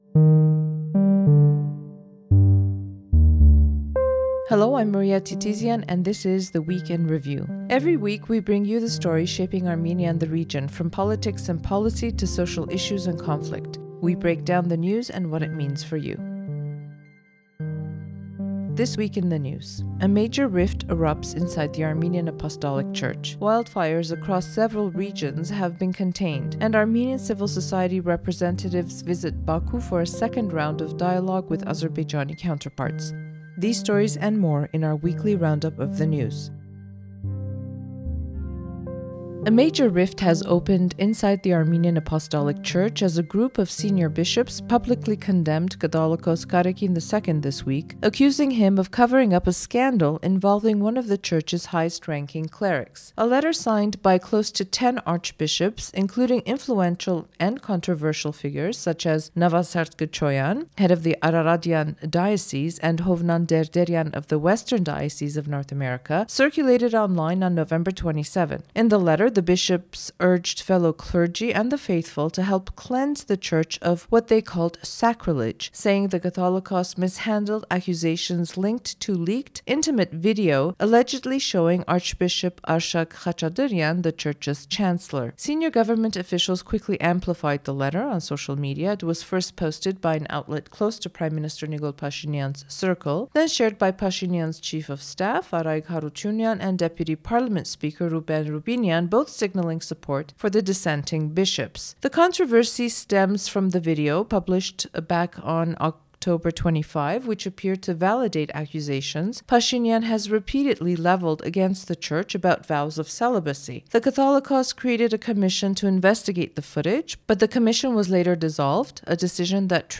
EVN Reports twice-a-week podcast keeps you up to date on everything in Armenia. Listen to interviews, features, and our Week in Review news roundup to hear whats happening and why its important.